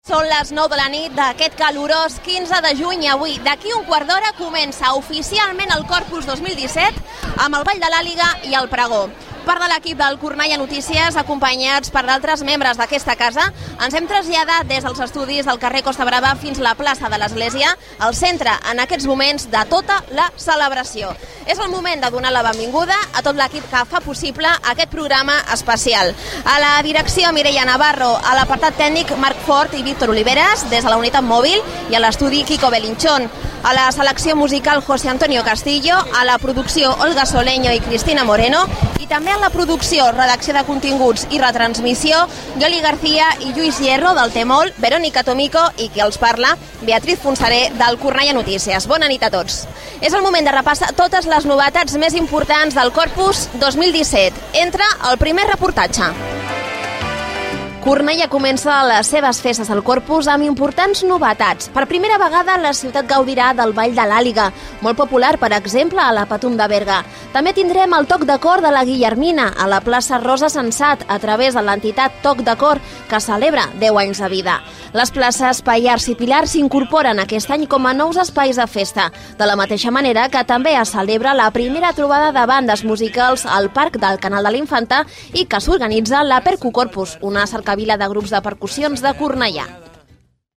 Transmissió des de la plaça de l'Església del Corpus.
Informatiu